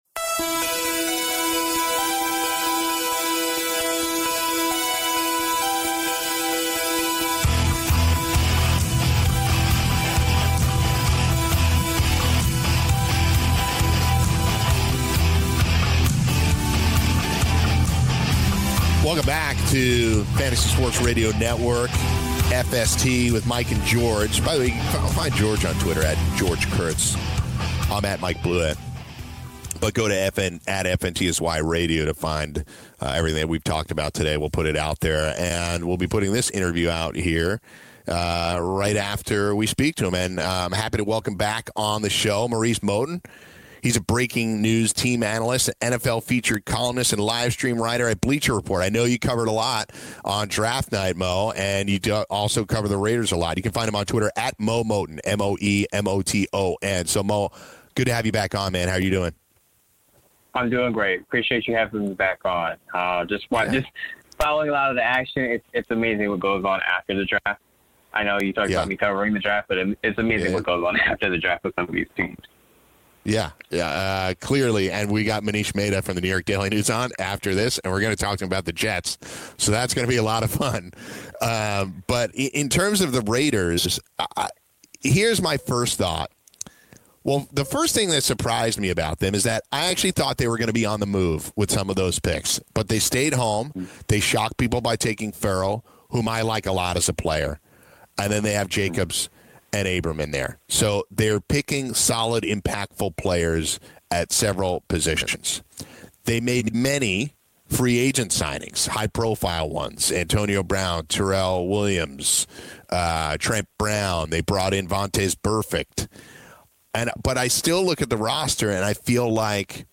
Interview on the Oakland Raiders